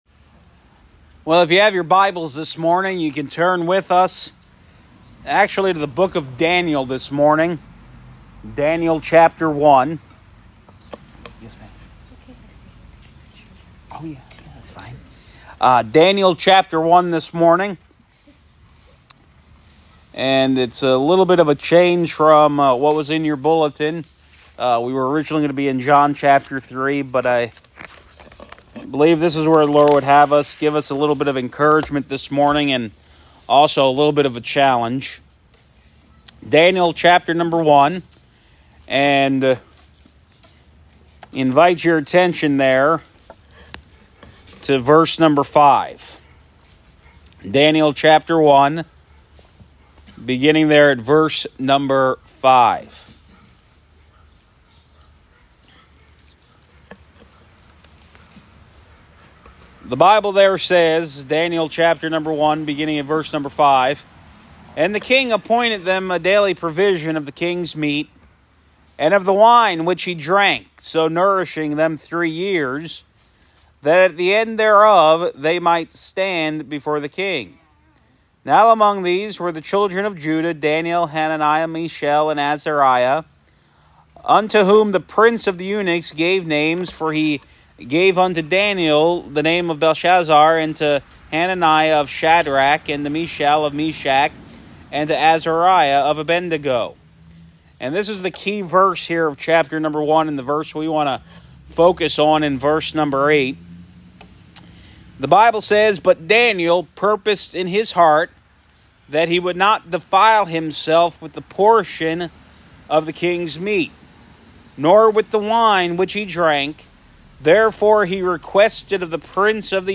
Sermons | First Baptist Church of Sayre, PA